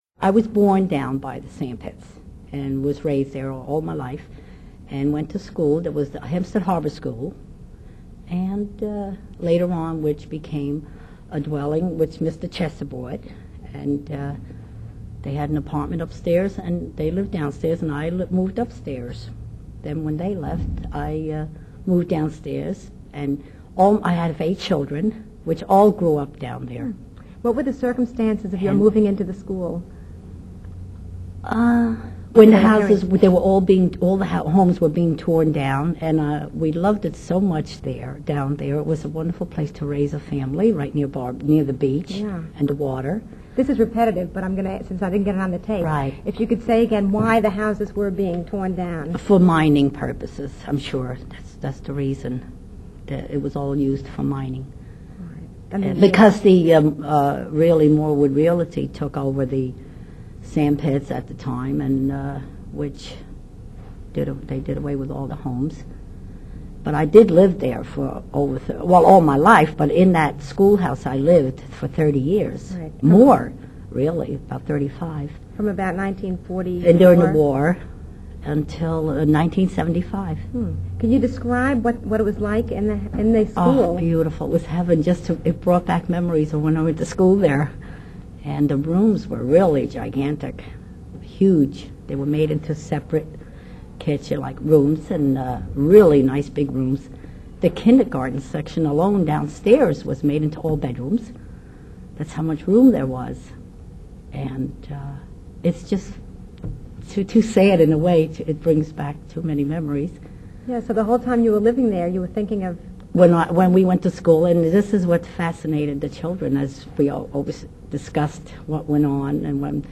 The following interview is one of a series of tape-recorded memoirs in the Port Washington Public Library Community Oral History Program.